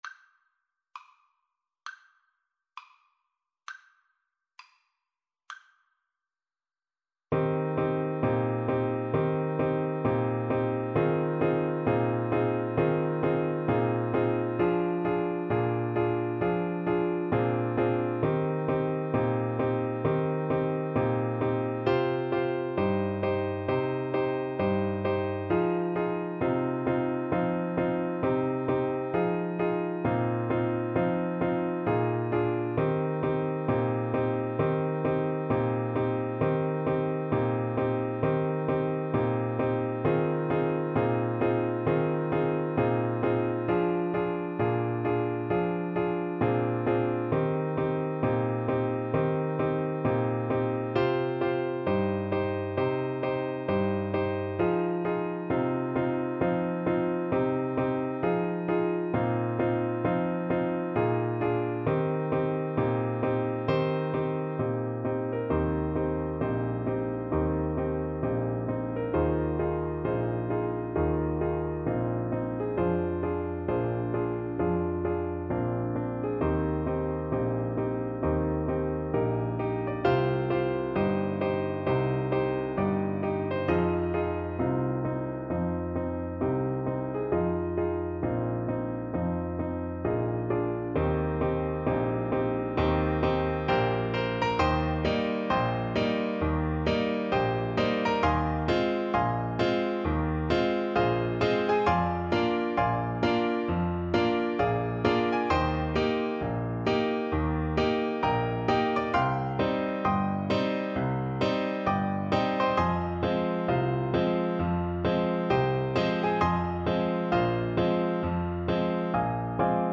Blues Tempo (=66)
Jazz (View more Jazz Tenor Saxophone Music)